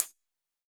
Index of /musicradar/ultimate-hihat-samples/Hits/ElectroHat A
UHH_ElectroHatA_Hit-10.wav